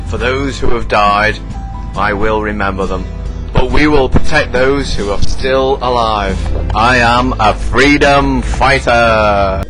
Speech - For those who have died I will remember them but we will protect those who are still alive for I am a freedom fighter